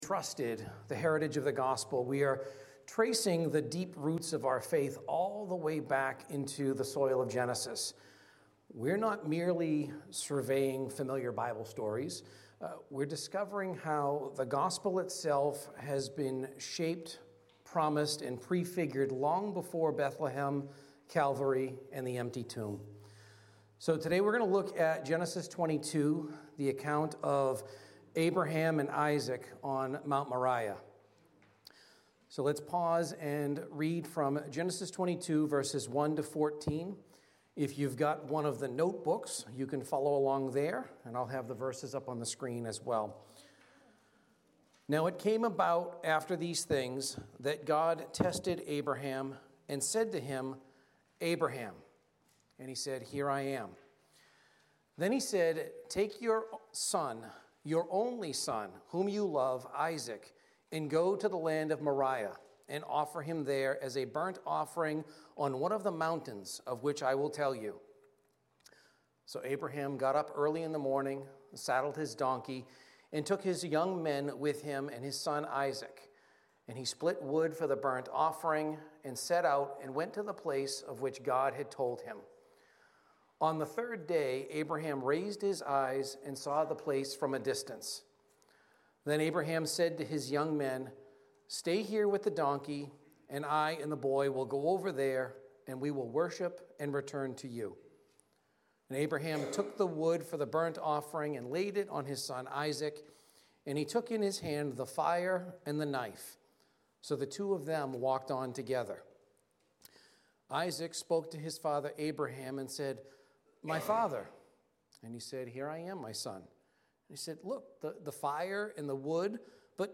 Entrusted-Foretold-Proclaimed Service Type: Celebration & Growth « Restoration in Christ Abraham